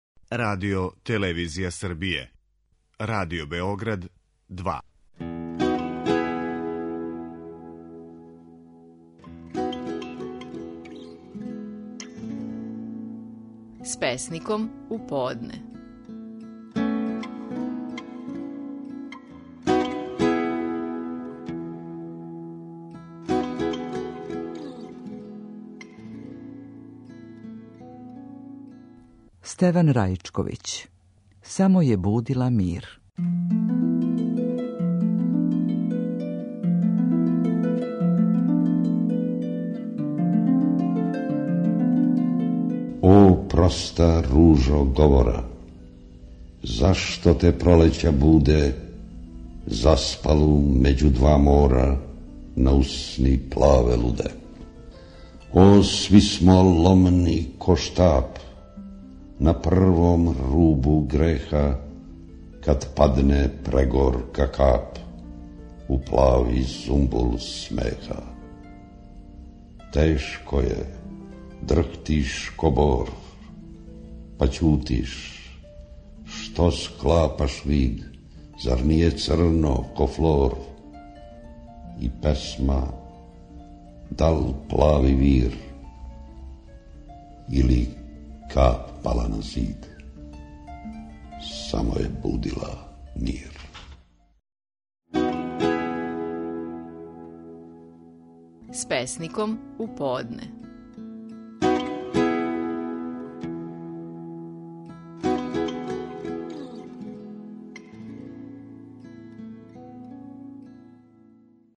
Стихови наших најпознатијих песника, у интерпретацији аутора.
Стеван Раичковић говори песму: "Само је будила мир".